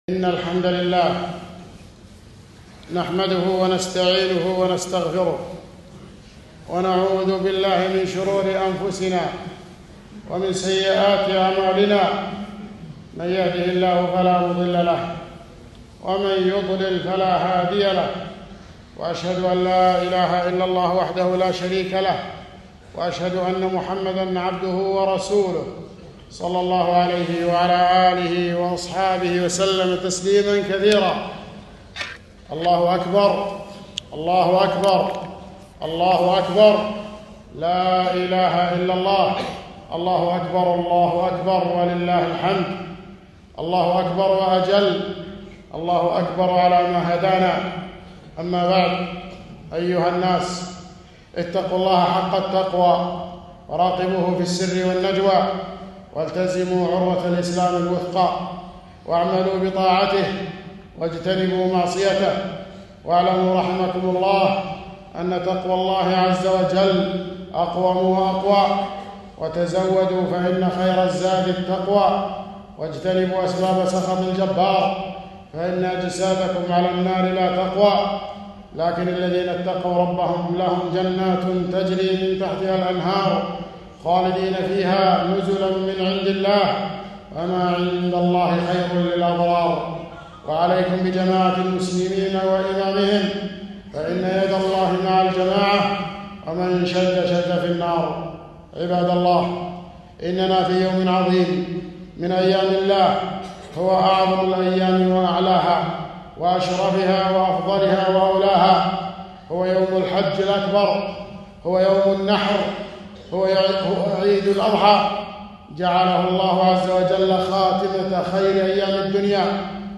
خطبة عيد الأضحى ١٤٤٠ هجري